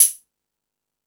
Hip Hop(11).wav